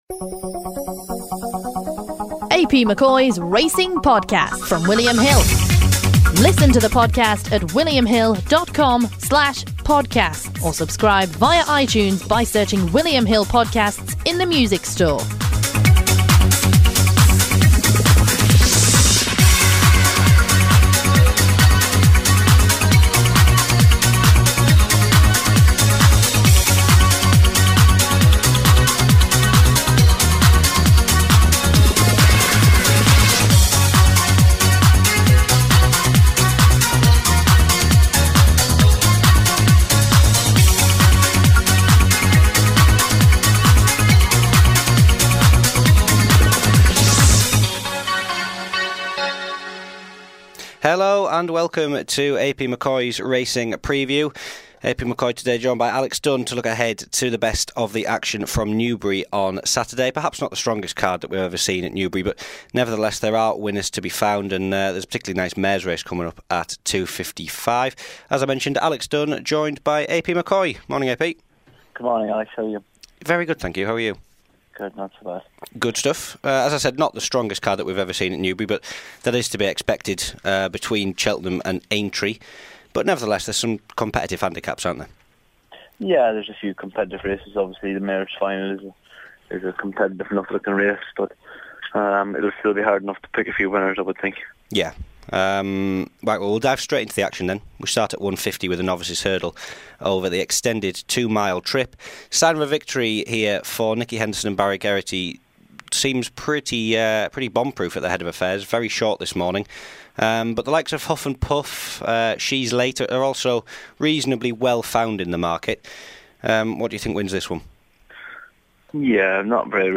Champion jockey AP McCoy